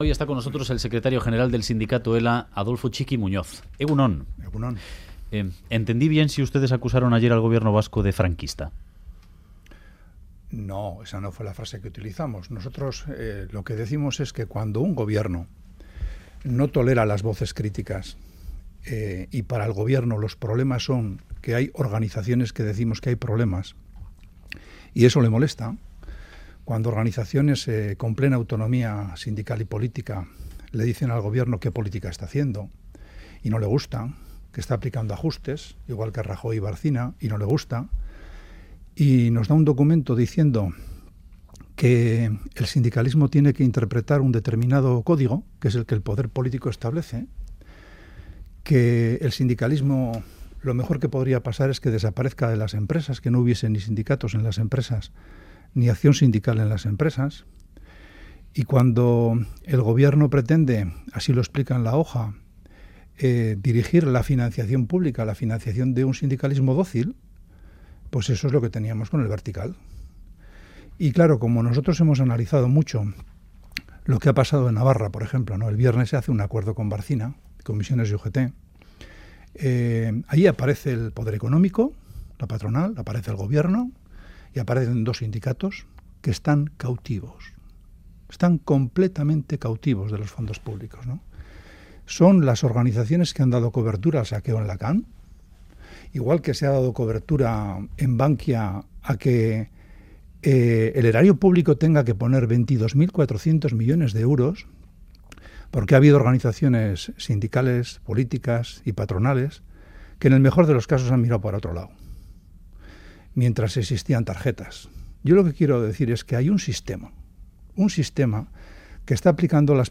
En entrevista al Boulevard de Radio Euskadi